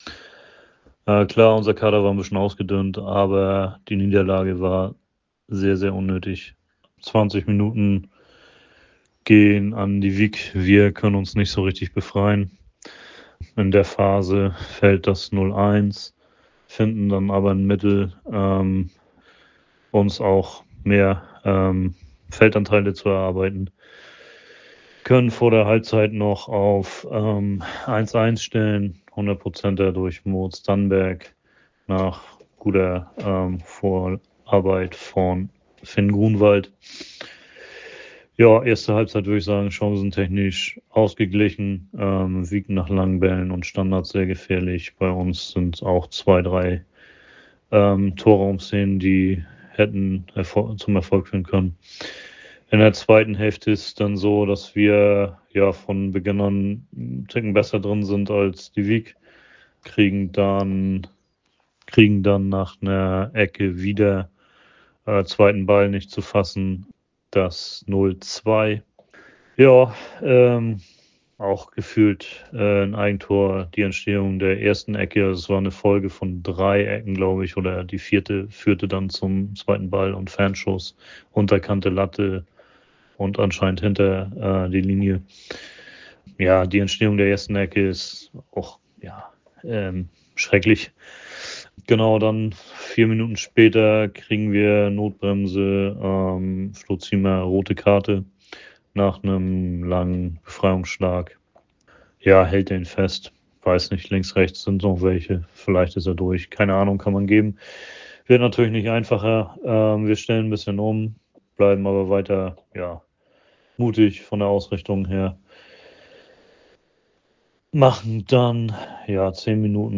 Stimme zum Spiel